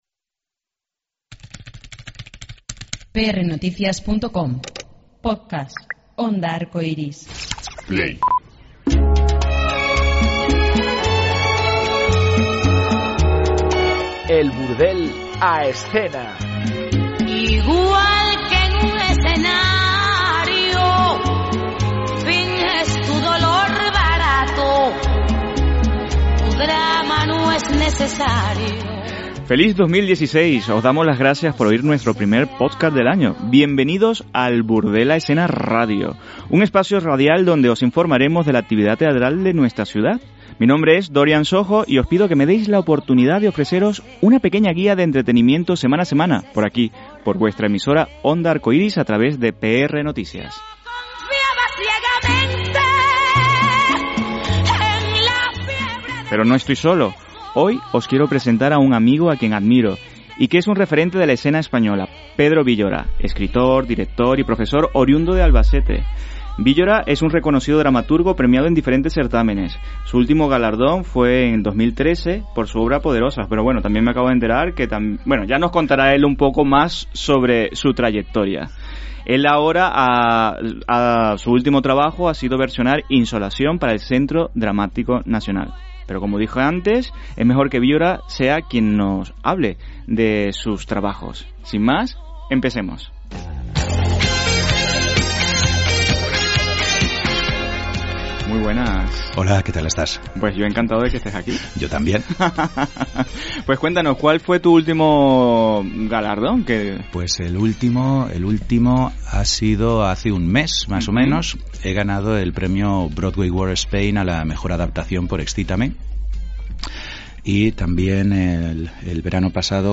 Bienvenidos a El Burdel a escena Radio. Comenzamos el año entrevistando